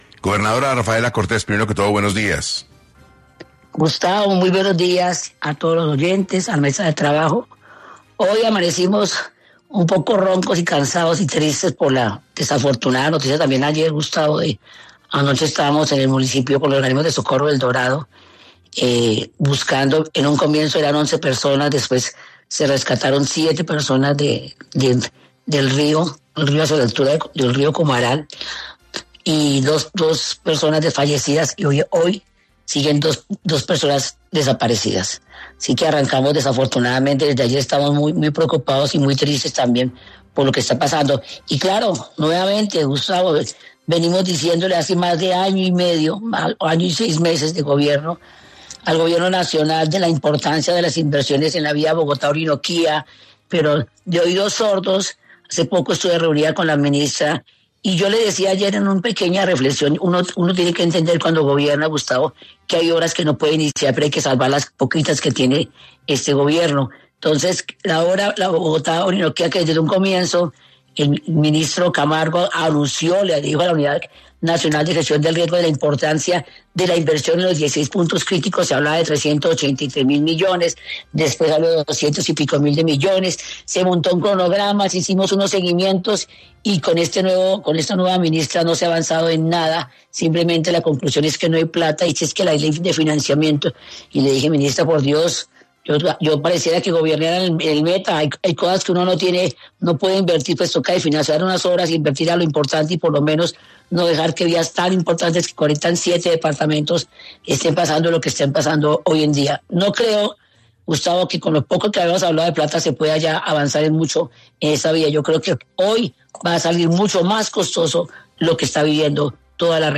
En entrevista con 6AM, la gobernadora del Meta, Rafaela Cortés, habló de los $300 mil millones que el gobierno había prometido para invertir en la vía al Llano, esto hace ya más de un año.